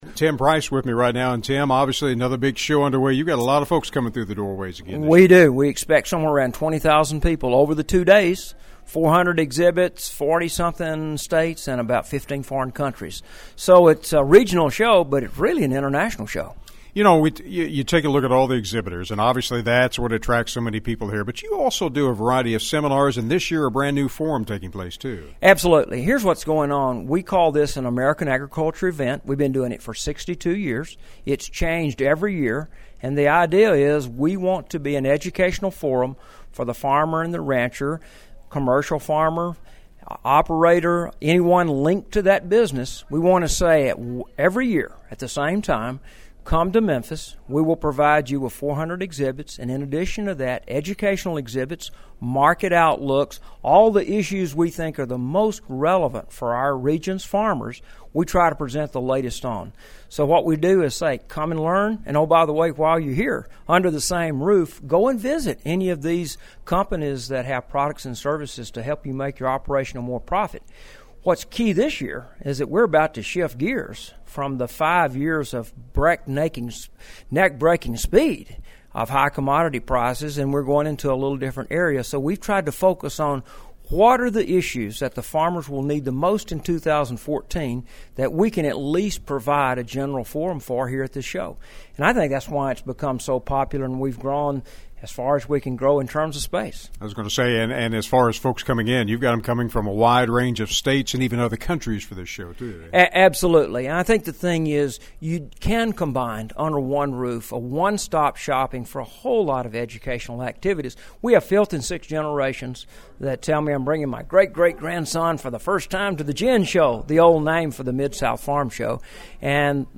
It’s day two for the 62nd annual Mid-South Farm & Gin Show at the Memphis Cook Convention Center in Memphis, Tennessee. Farmers from many states are in town for the annual event which is open today from 9:00am-4:30pm.